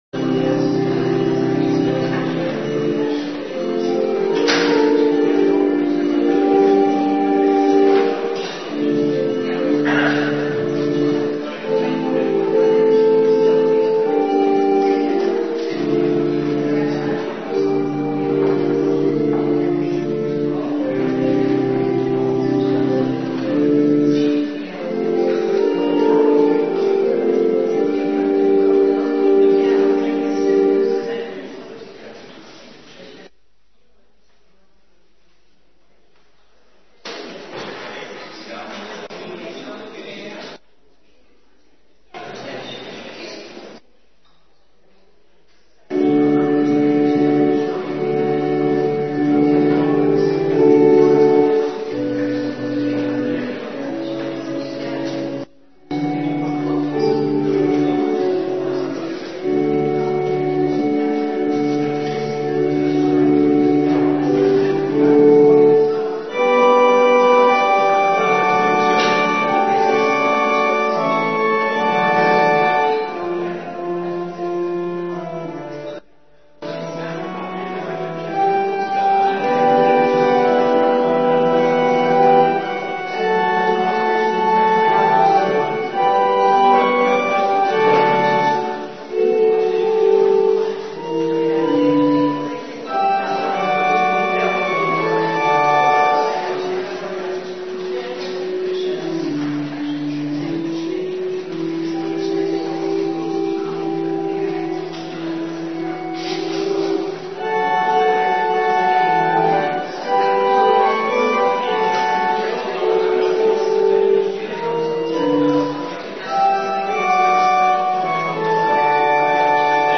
ORDE VAN DIENST 2
voor de Bethelkerk in Weert Zondag 10 februari